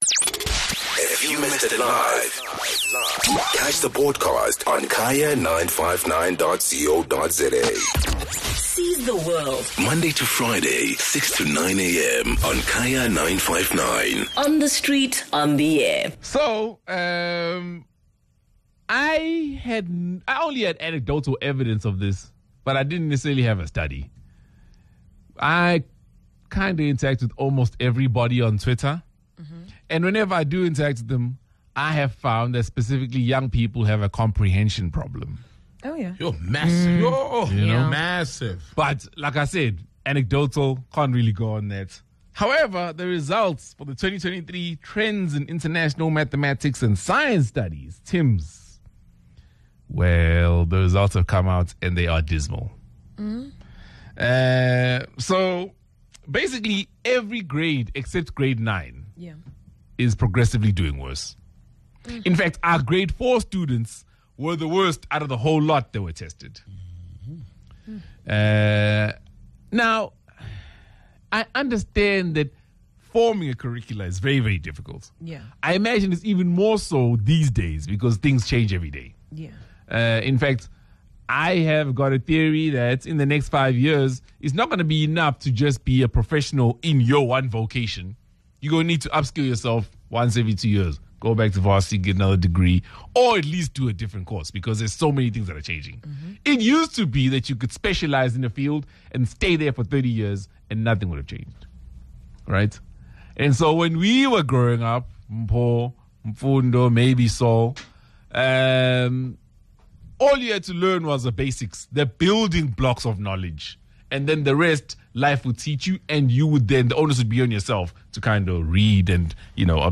The team discussed these results, examining whether the quality of education kids are receiving these days has deteriorated. They, together with listeners, explored roles parents can play to improve this situation.